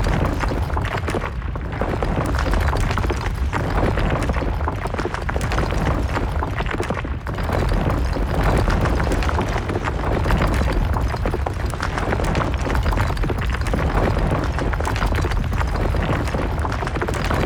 Rockfall_Loop_02.wav